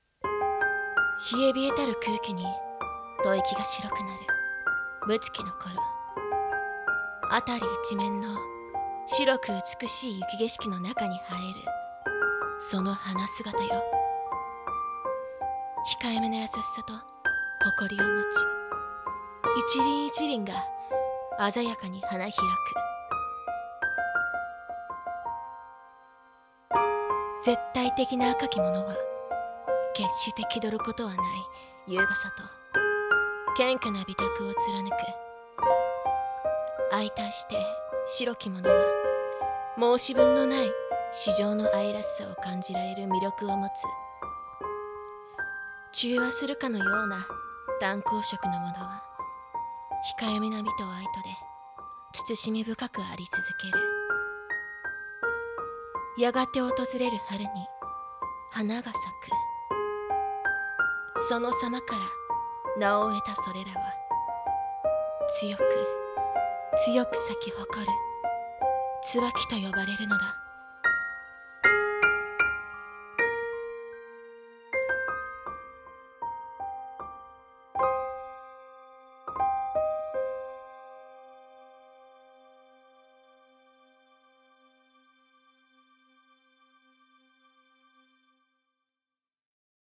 【声劇台本】花言葉〜椿(つばき)〜